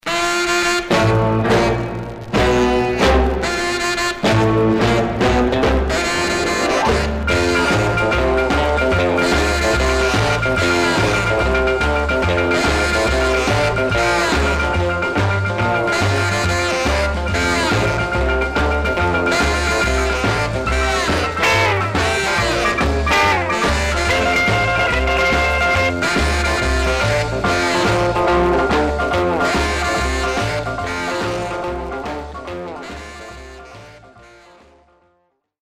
Surface noise/wear
Mono
R&B Instrumental